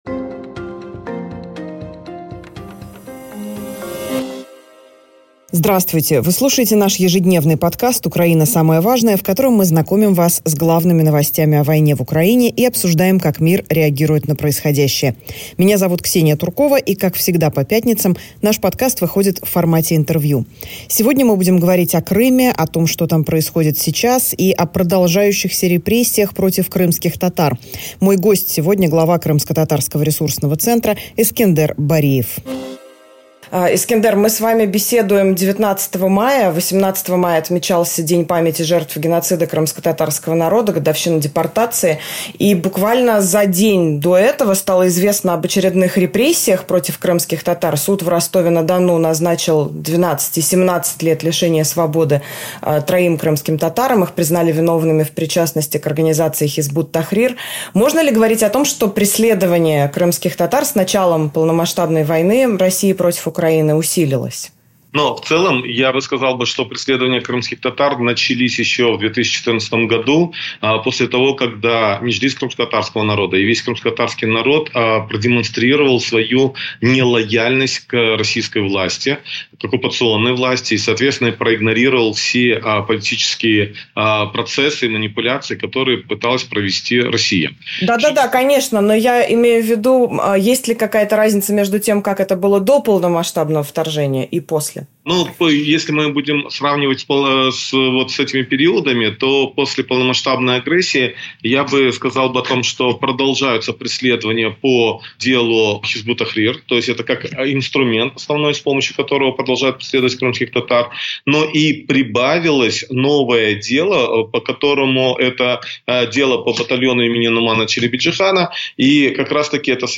Как всегда по пятницам, подкаст выходит в формате интервью.